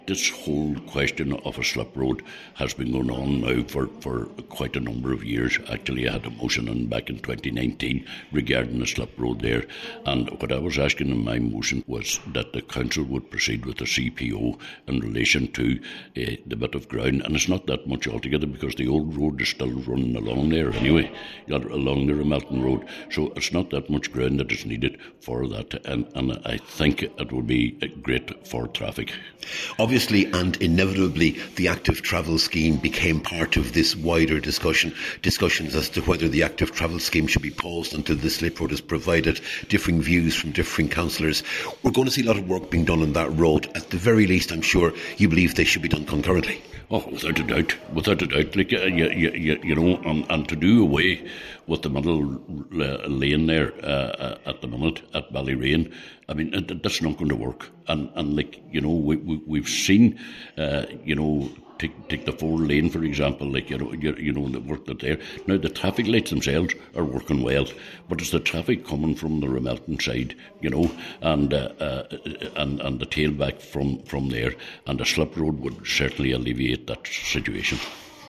Cllr Coyle says officials must continue to pursue the slip road proposal: